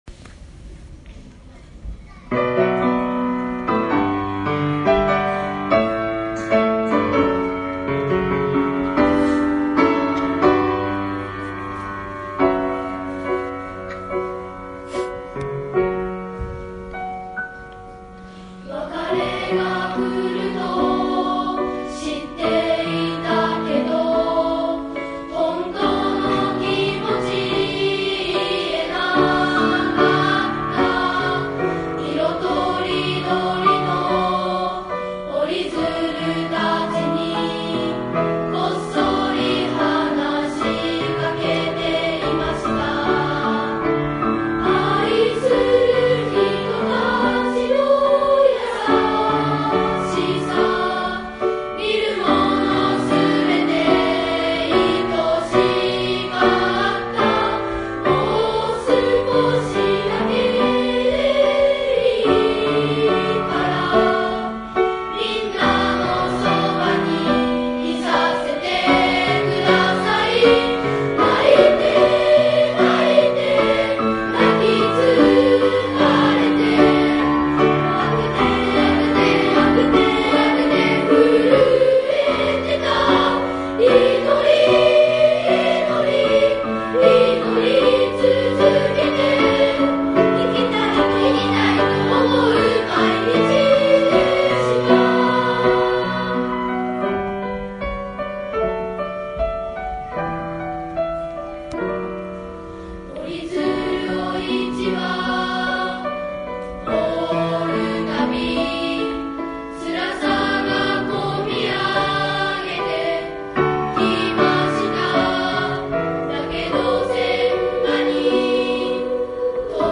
１１月１９日（土）平成２８年度校内歌声発表会の第１報です。
最高学年の歌声をお聴きください。